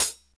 Closed Hats
Hat (33).wav